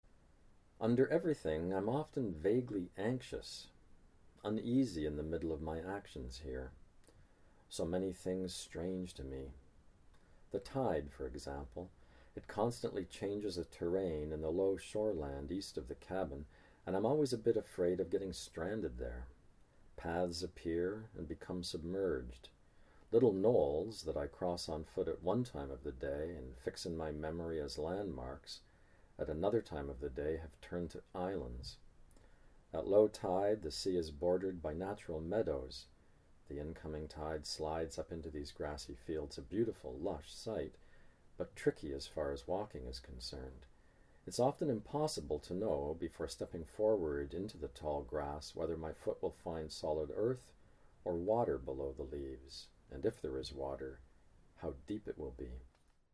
John Steffler reads [Under everything I'm often] from The Grey Islands